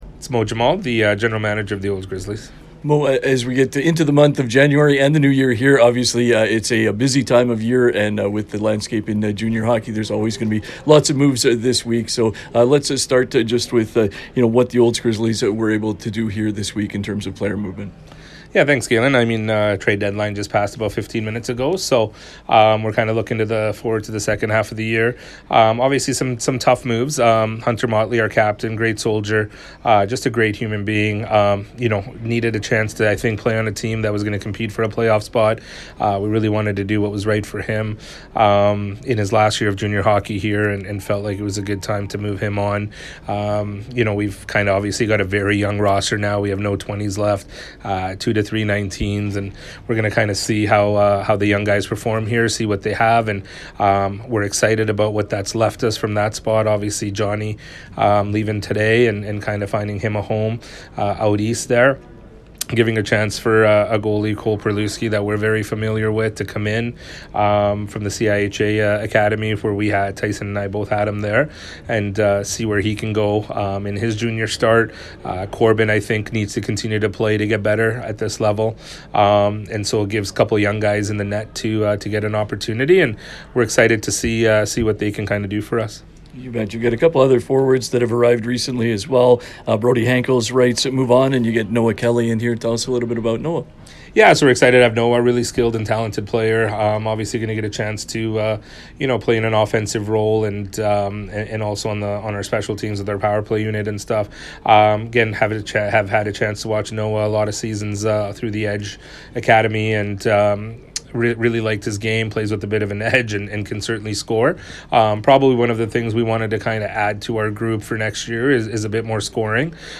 post-trade deadline conversation